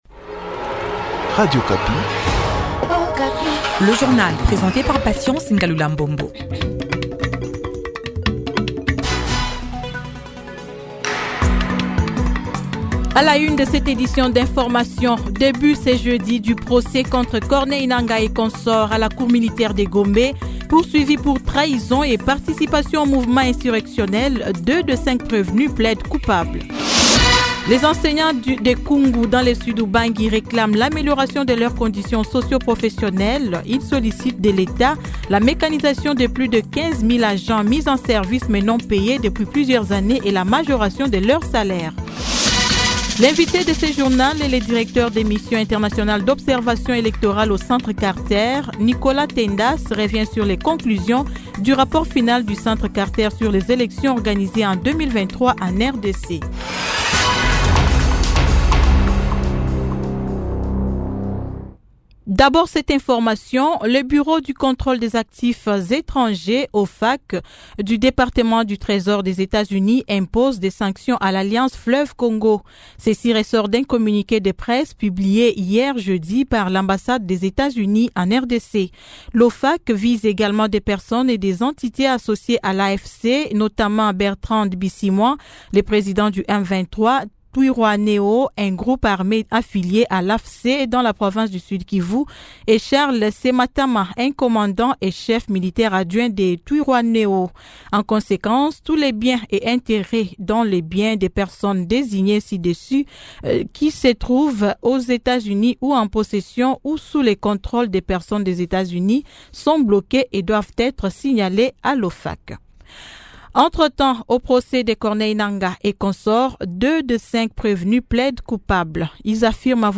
Journal matin 08H